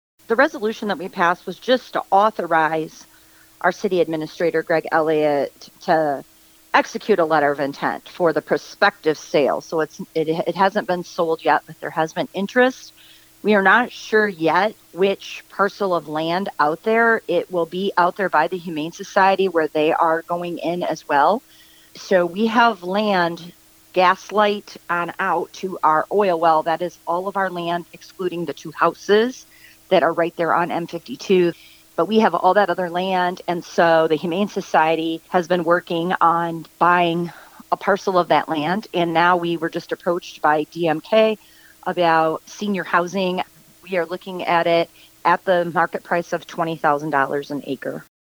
Adrian Mayor Angie Heath spoke to WLEN News about what the Commission did, and the proposed business moving into the area…